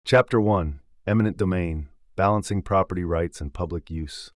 Eminent Domain and Property Rights Challenges and Controversies Audio Book: Listen Online for Free